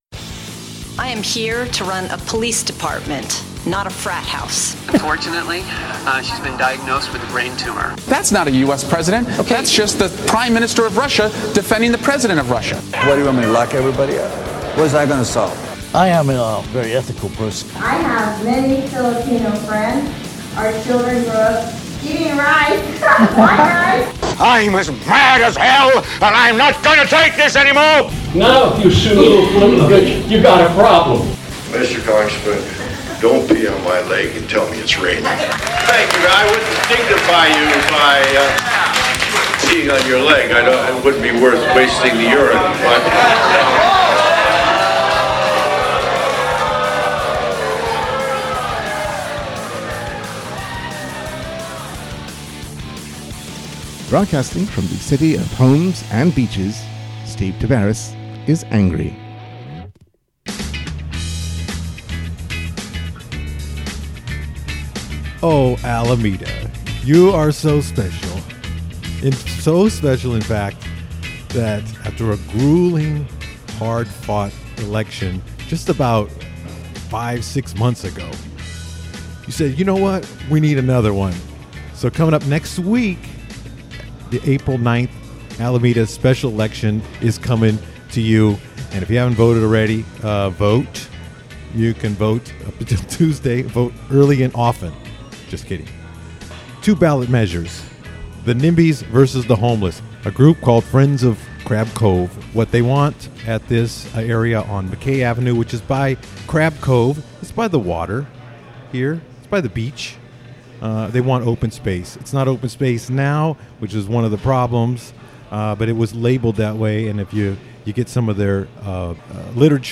Alameda Vice Mayor John Knox White joins the program to discuss the controversial special election.